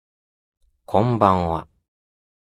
Character Voice Files